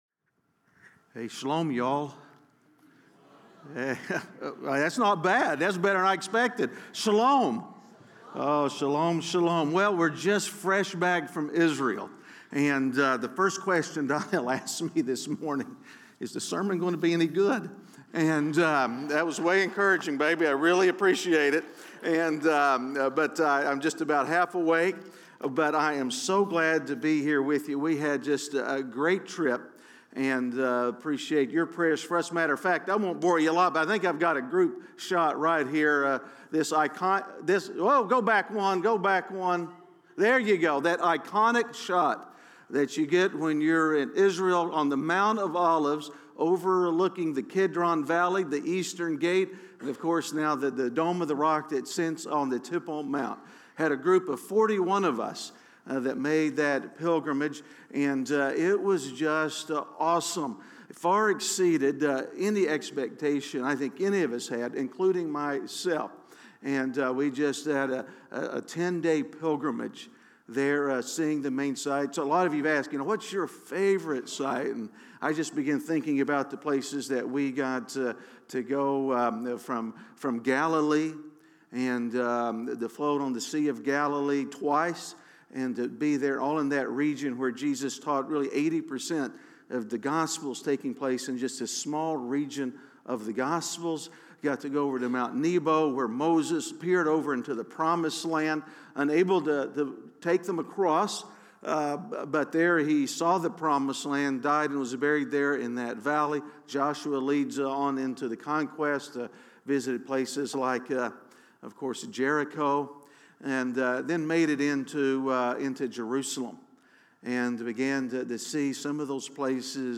A message from the series "Lies Men Believe." It's easy to worry about money.